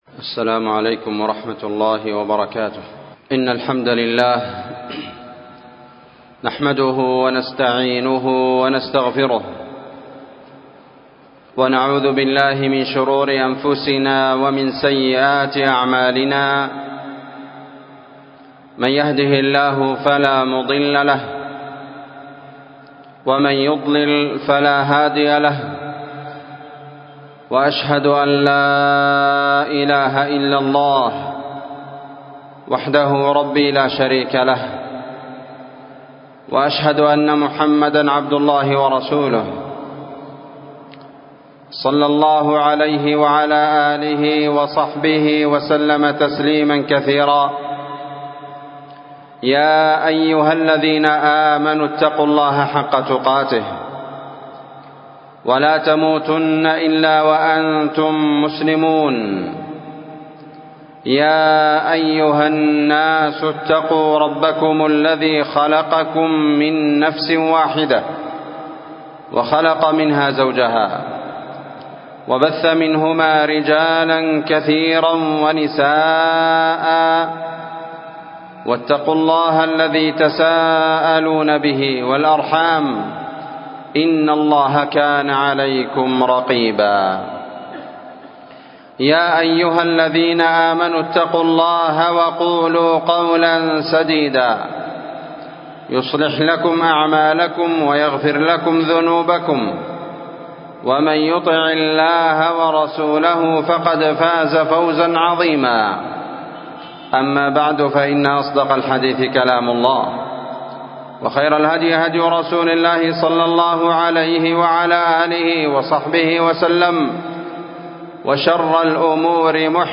خطبةجمعة12صفر1446هـــ (حرص النبي صلى الله عليه وسلم على أمته في قوله مثلي ومثلكم)